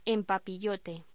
Locución: En papillote
voz